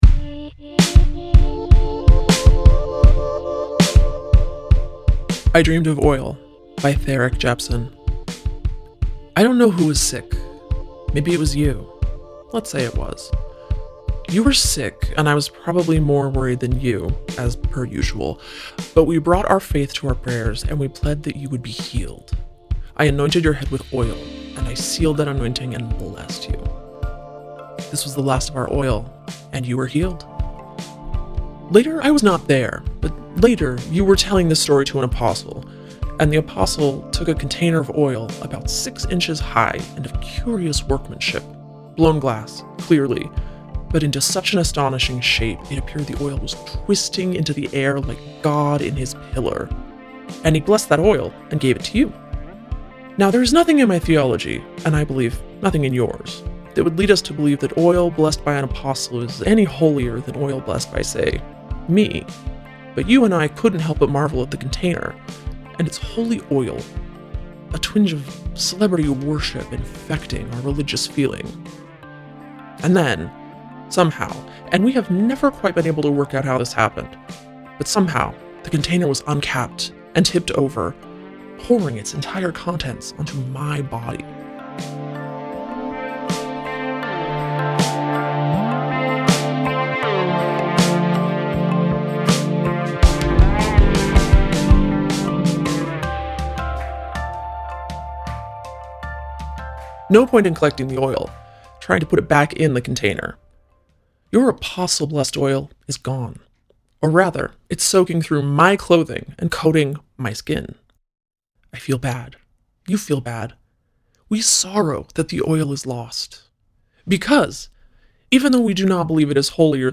Audio Story